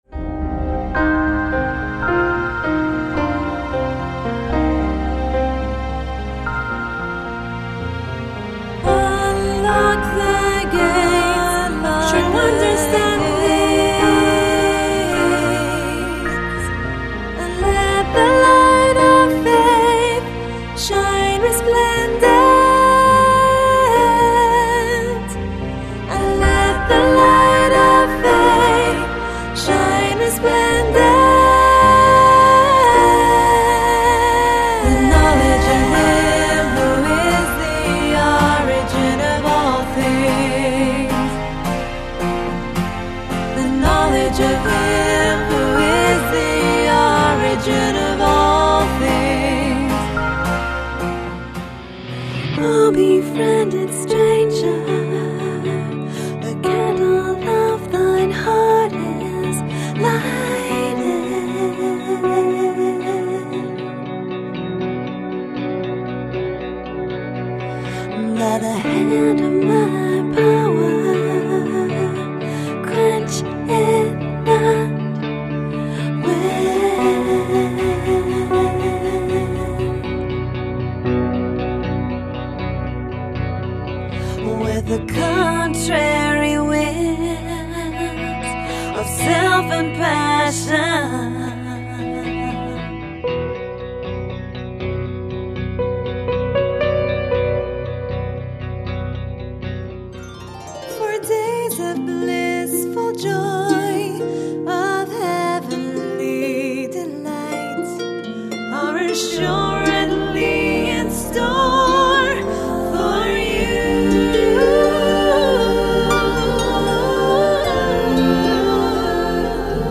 POP PRODUCTION DEMO 01, 02